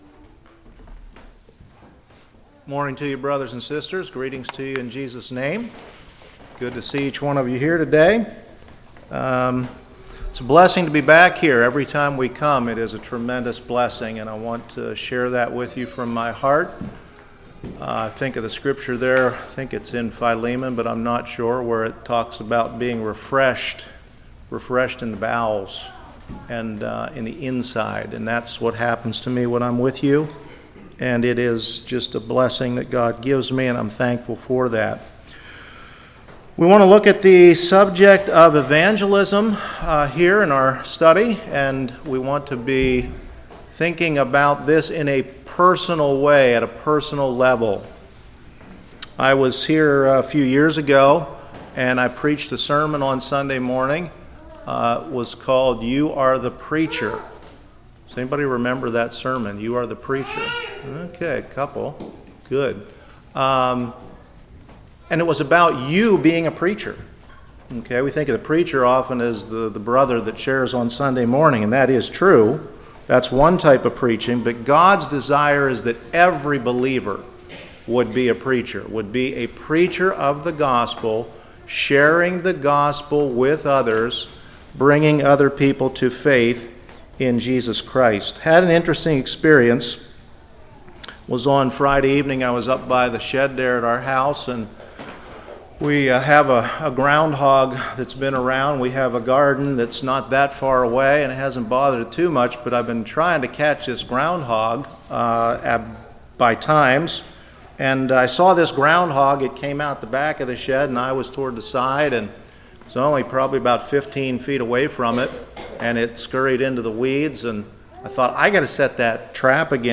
Service Type: Sunday School